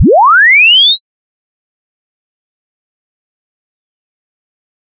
envolvente-de-tono-1.wav